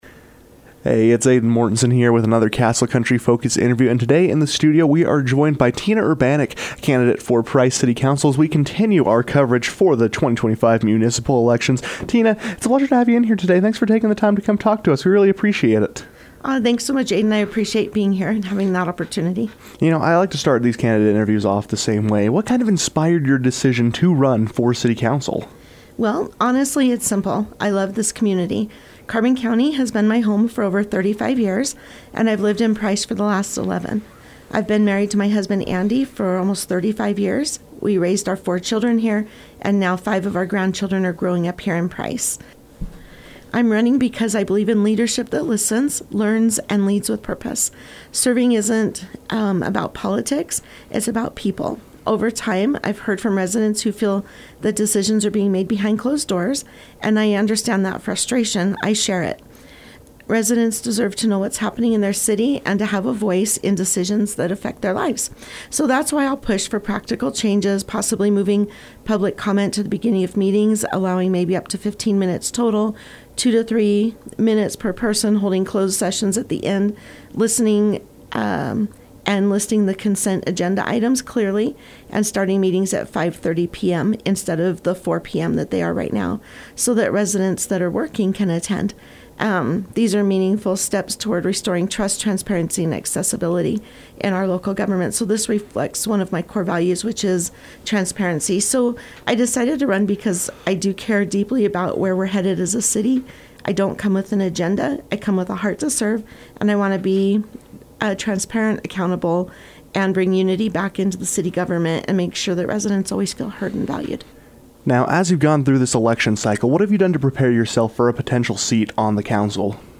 All candidates in the 2025 municipal election cycles are entitled to one free interview.